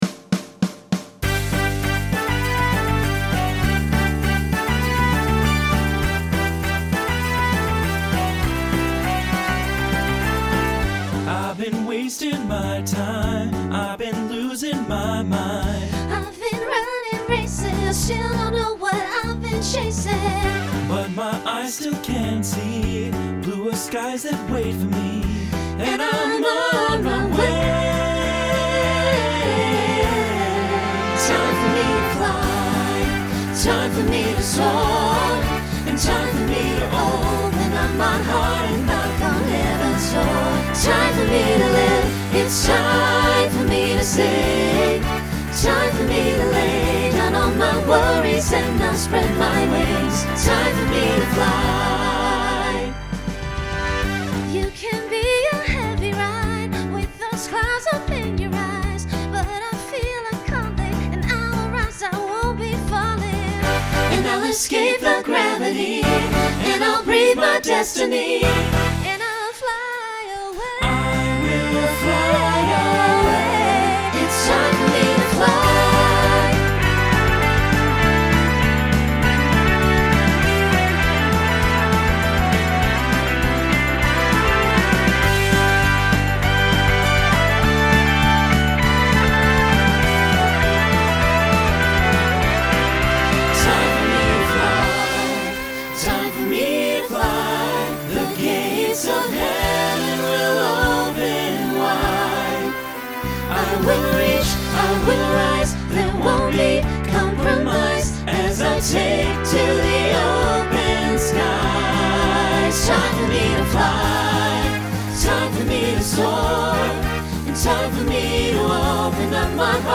Pop/Dance Instrumental combo
Voicing SATB